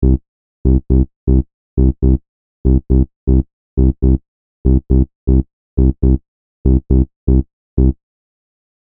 Here I have set up a basic mono synth and sent it to two separate aux. tracks.
This is how it sounds just before the delay is added…still pretty mono.